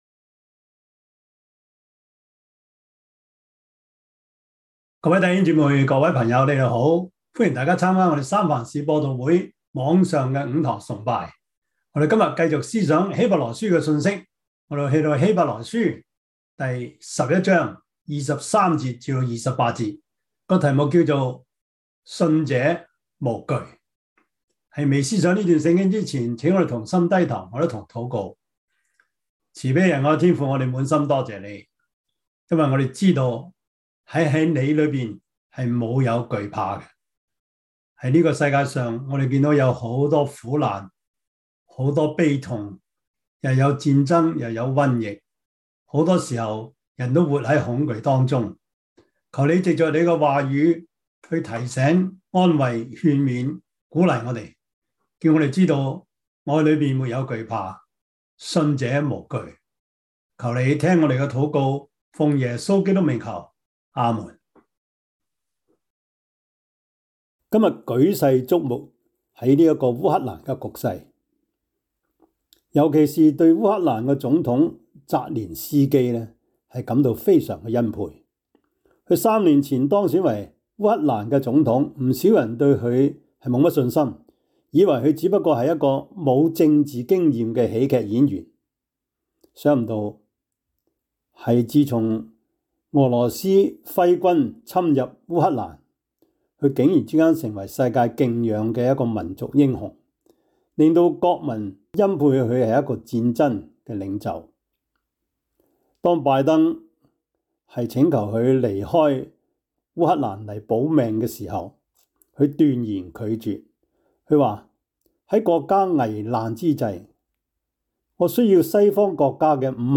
Service Type: 主日崇拜
Topics: 主日證道 « 從耶西的本必發一條 摩西五經 – 第十課 »